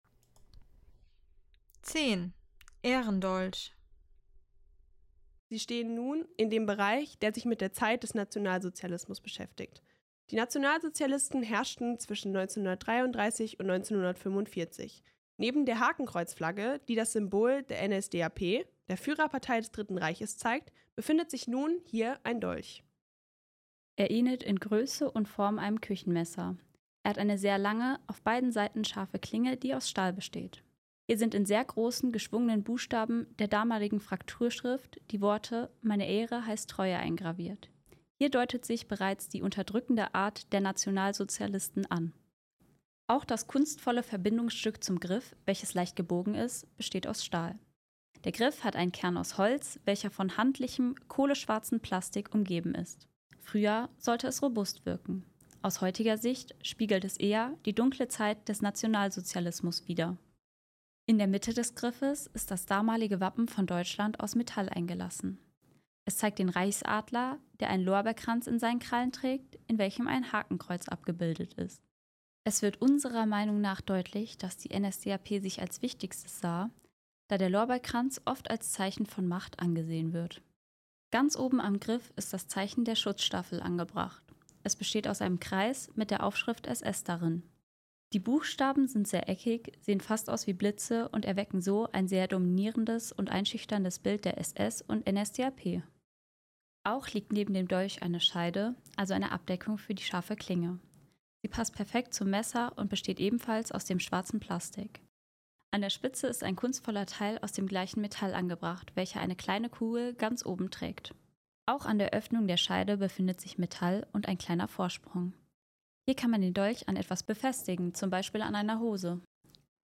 Audioguide - Station 10 Ehrendolch